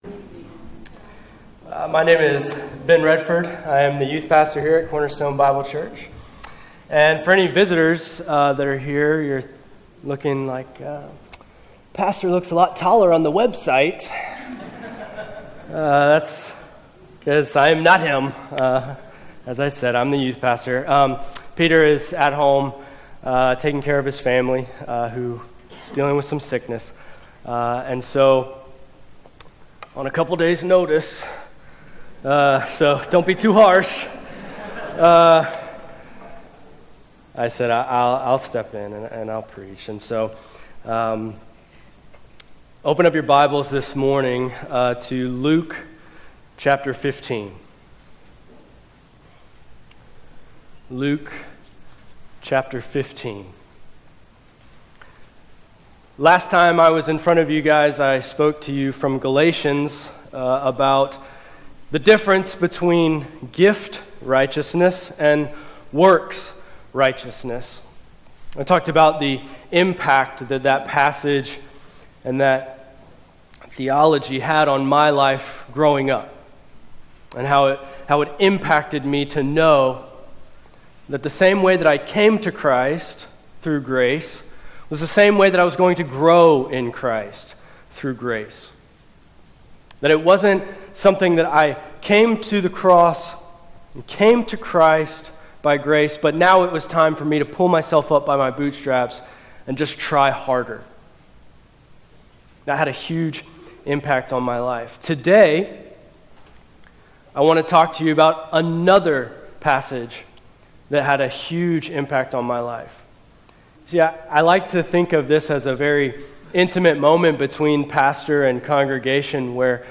Sermon Detail